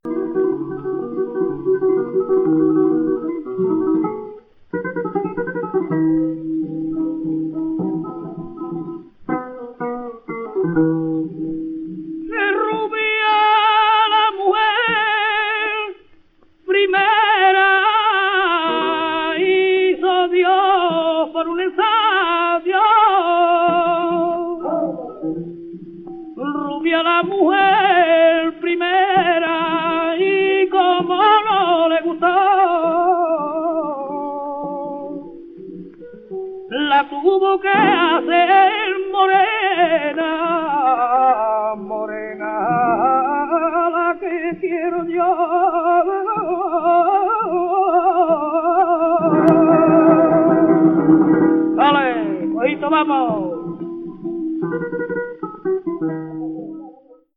VI) Granaínas